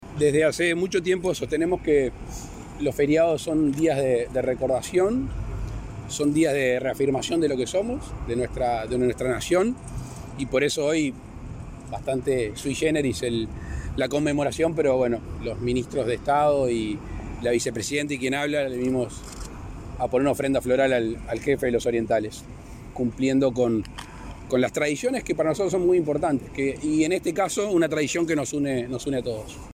“Hoy venimos a rendir homenaje al Jefe de los Orientales, al que dio el primer destello de nacionalidad”, destacó el mandatario, Lacalle Pou, en la plaza Artigas, en Sauce, donde se celebró el 256.° aniversario del Natalicio de José Gervasio Artigas.